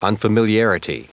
Prononciation du mot unfamiliarity en anglais (fichier audio)
unfamiliarity.wav